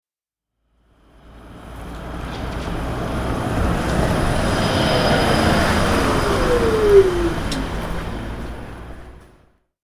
دانلود صدای اتوبوس 10 از ساعد نیوز با لینک مستقیم و کیفیت بالا
جلوه های صوتی